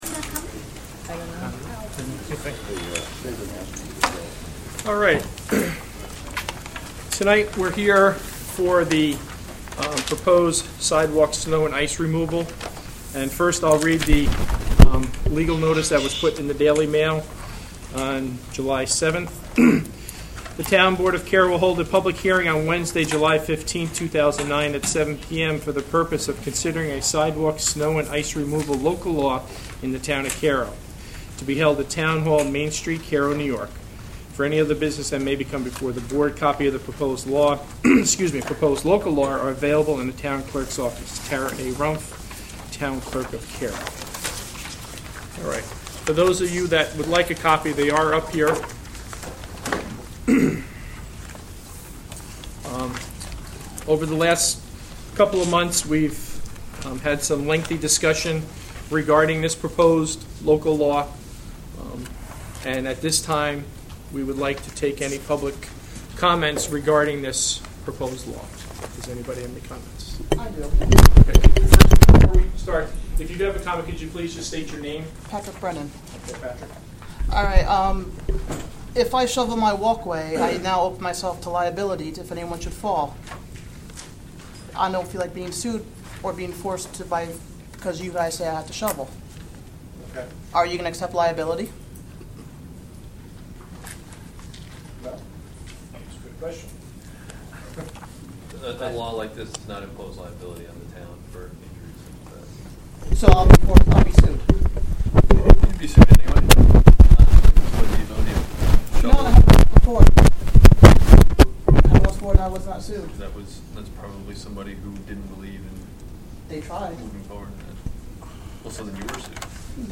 Town of Cairo public hearing about sidewalk snow and ice removal proposed law_071509 (Audio)
Town of Cairo public hearing about sidewalk snow and ice removal proposed law_071509 (Audio) Jul 15, 2009 artists Town of Cairo, NY From Cairo Town Hall.
cairosidewalklawpublichearing.mp3